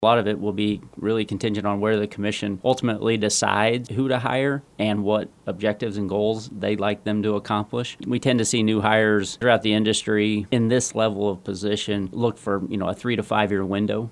Hilgers also detailed some of the process so far and what lies ahead.